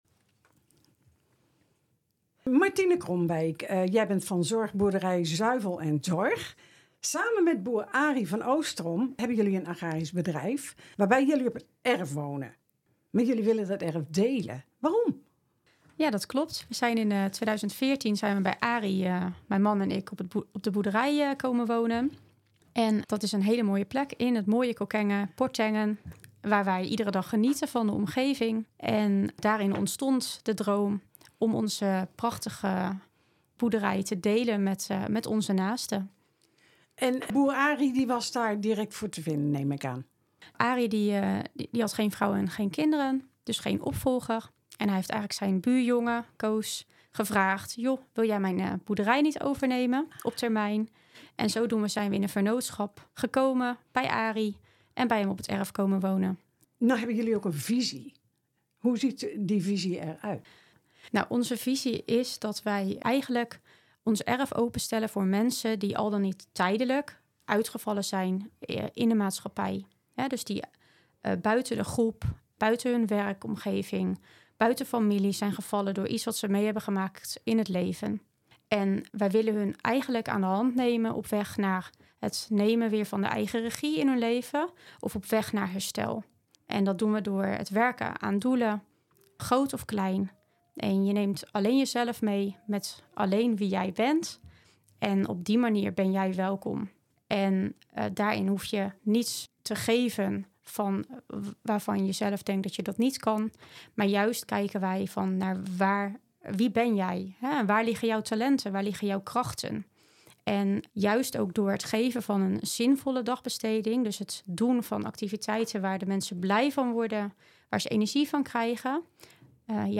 INTERVIEW MET RTV STICHTSE VECHT